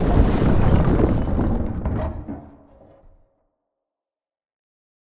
scpcb-godot/SFX/Character/Apache/Crash2.ogg at ae8b17a347ad13429a7ec732a30ac718cea951e4
Crash2.ogg